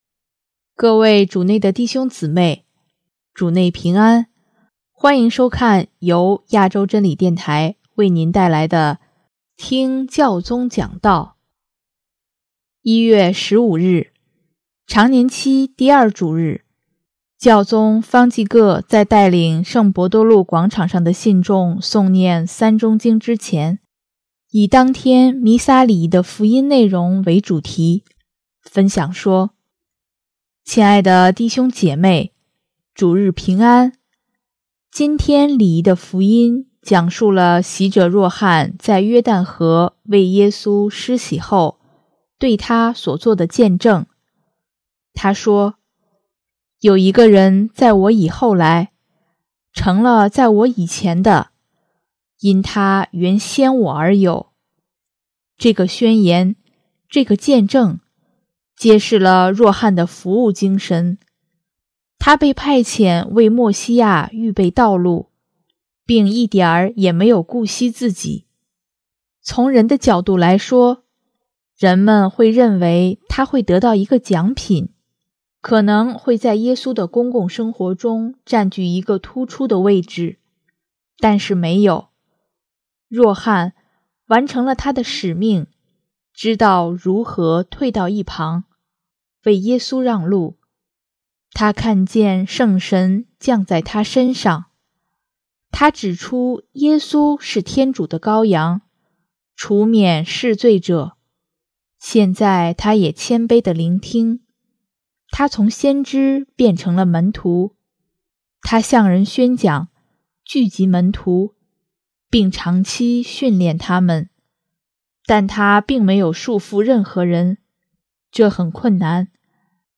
【听教宗讲道】|摆脱依恋，为主让路，给予他人自由
1月15日，常年期第二主日，教宗方济各在带领圣伯多禄广场上的信众诵念《三钟经》之前，以当天弥撒礼仪的福音内容为主题，分享说：